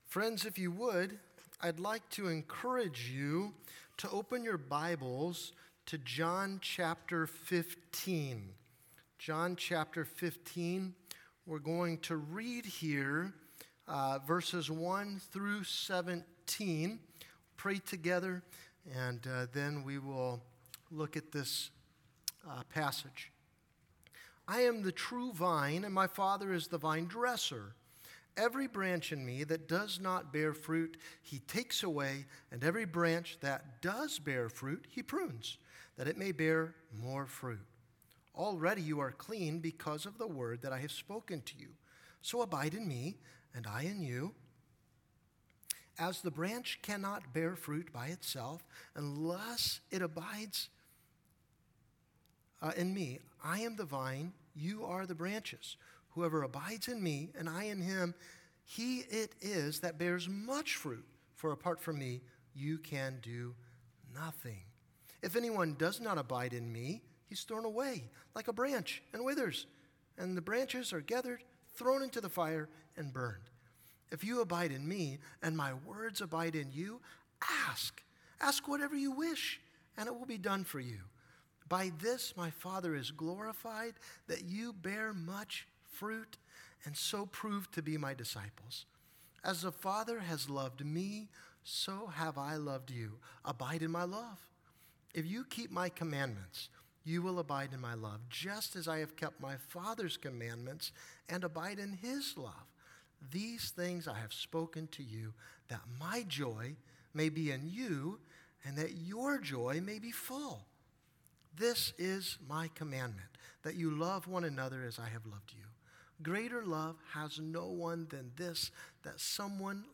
Sermons | California Road Missionary Church
Guest Speaker